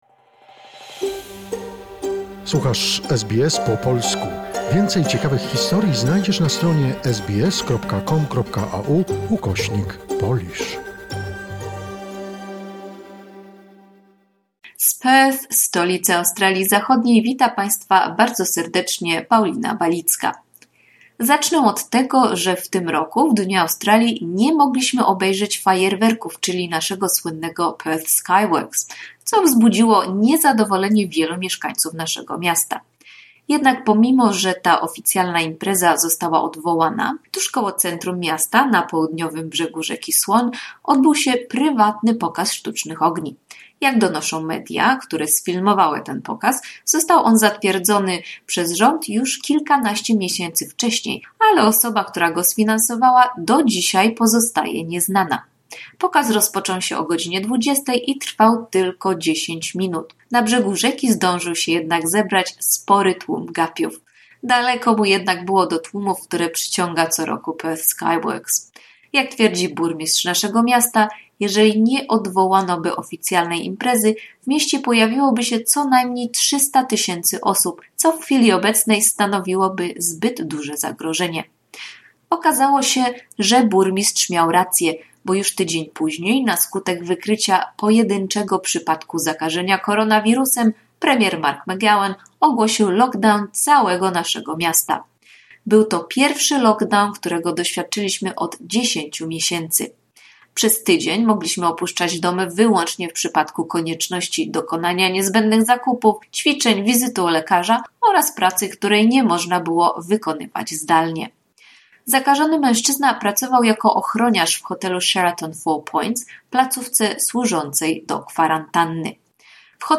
Commentary of the latest events form WA